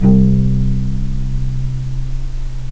vc_pz-C2-pp.AIF